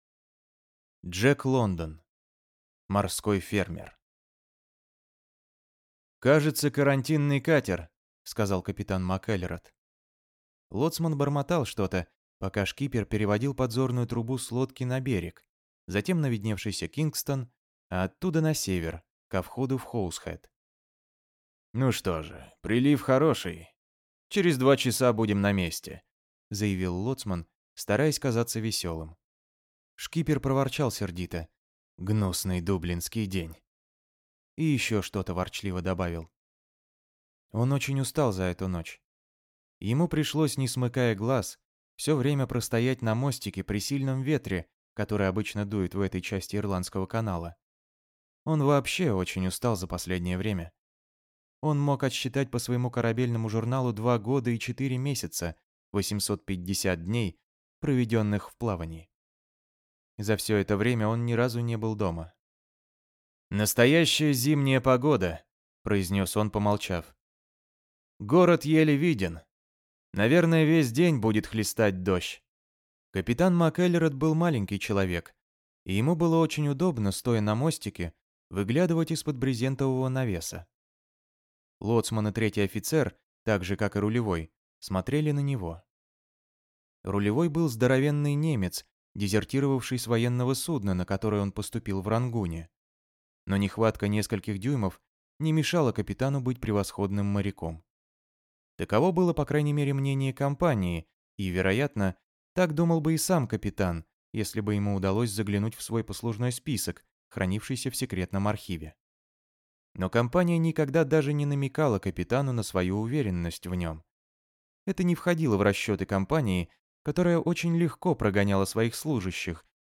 Аудиокнига Морской фермер | Библиотека аудиокниг